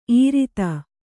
♪ īrita